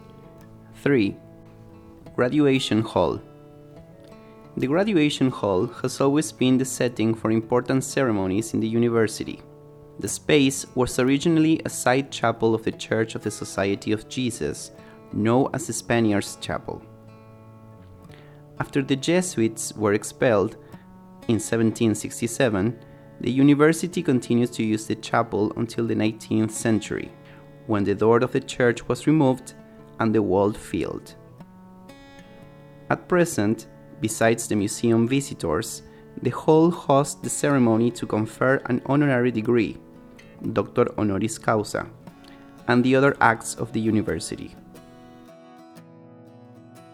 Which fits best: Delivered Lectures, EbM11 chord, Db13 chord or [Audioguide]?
[Audioguide]